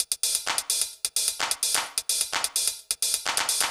TEC Beat - Mix 6.wav